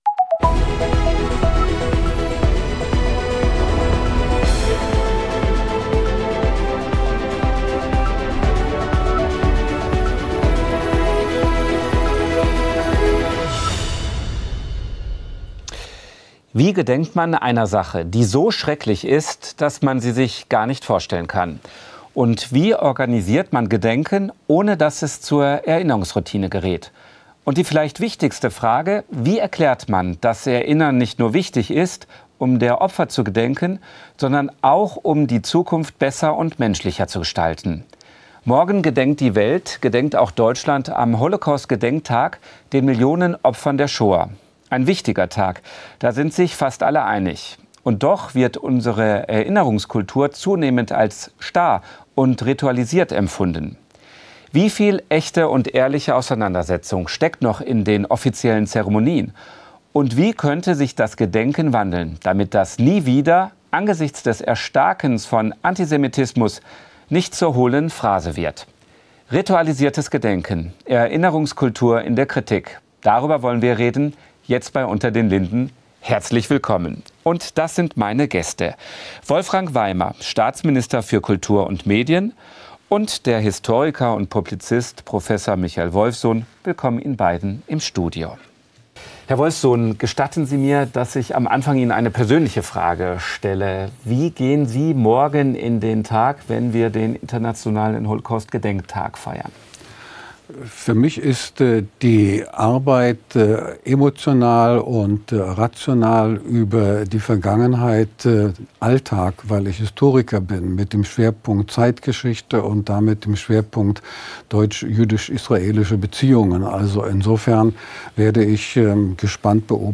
Ein wichtiger Tag, da sind sich fast alle einig - und doch wird unsere Erinnerungskultur zunehmend als zu starr und ritualisiert empfunden. Kulturstaatsminister Wolfram Weimer und Historiker Prof. Michael Wolffsohn diskutieren